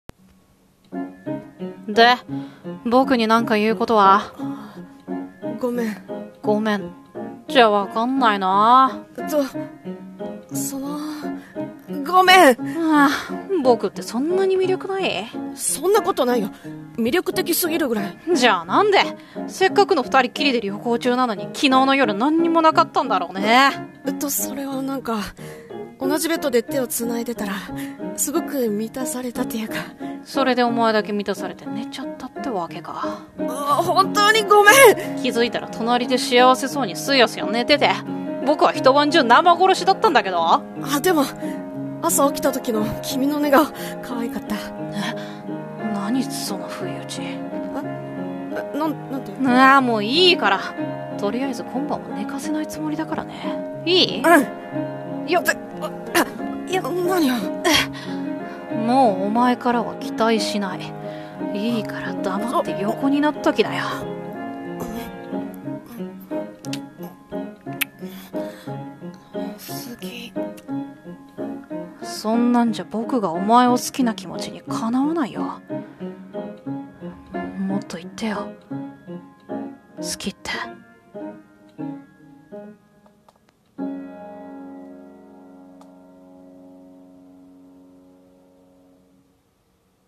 BL声劇】反省してよね